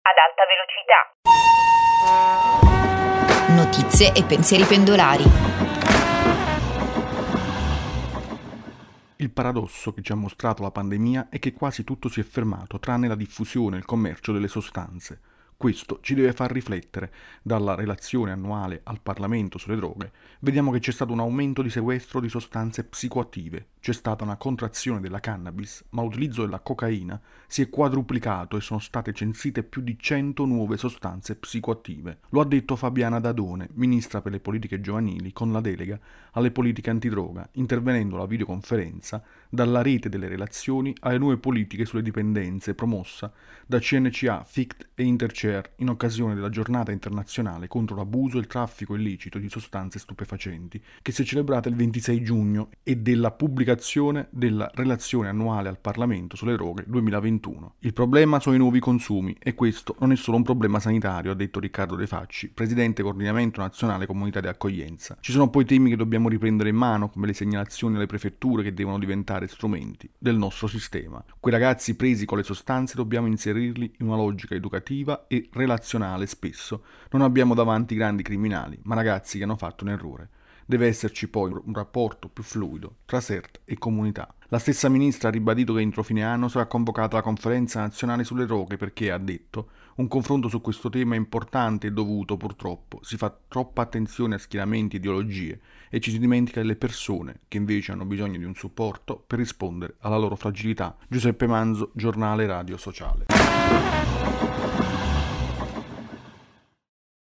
Lo ha detto Fabiana Dadone, ministra per le Politiche giovanili con delega alle politiche antidroga, intervenendo alla video conferenza “Dalla rete delle relazioni alle nuove politiche sulle dipendenze”, promossa da CncaFict e Intercear, in occasione della Giornata internazionale contro l’abuso e il traffico illecito di sostanze stupefacenti, che si è celebrata il 26 giugno, e della pubblicazione della Relazione annuale al Parlamento sulle droghe 2021